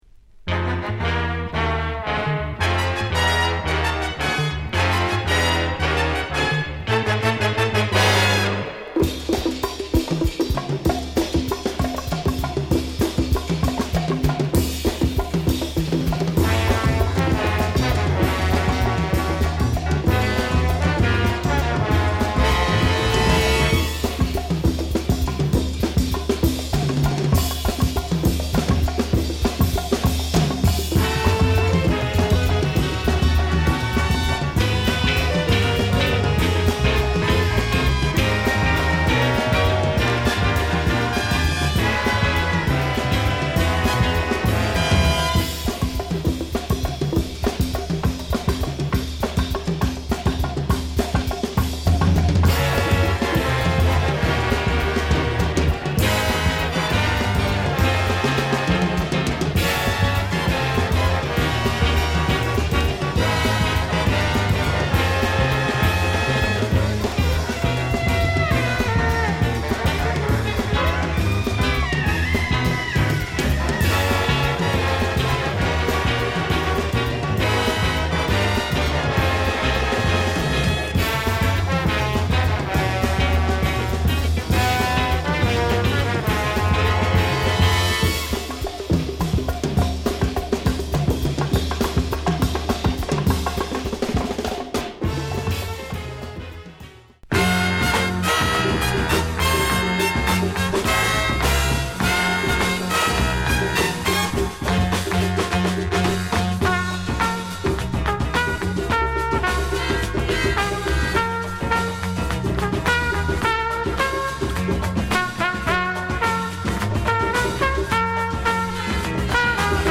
Beautiful West Indian / creole jazz